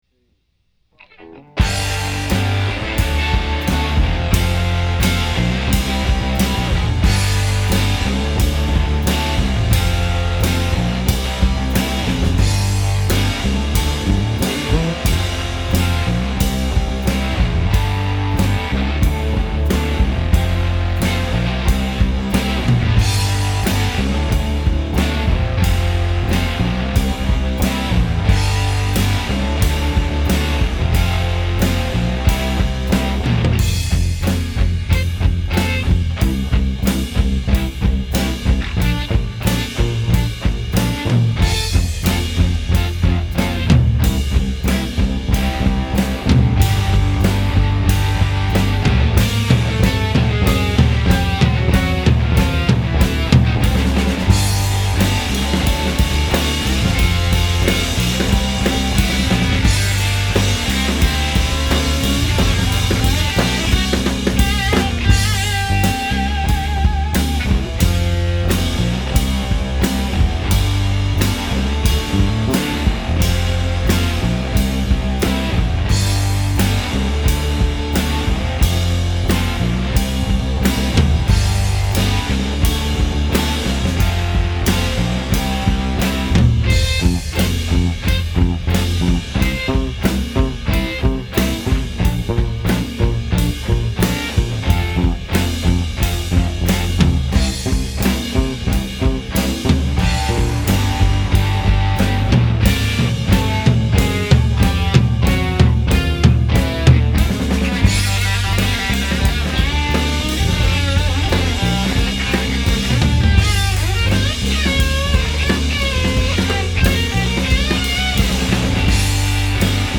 They were set up on side by side on a rug in between my weight bench (that never gets use).
After playing back some of the recording, we were all quite impressed with the separation we had having the amps in the different rooms. I thought there would be at least a little bleed from the drum mics, but we heard nothing, or almost nothing.
That night I was pretty excited and couldn’t sleep, so I stayed up listening to the tracks and made a rough mix in Logic:
We didn’t even know we would get to that point, and it was getting late, so the songs are rushed (tempo), mistakes were made, and we didn’t even tune first, but it was all just a test anyway.
Kill-Your-Sons-Test-Rough.mp3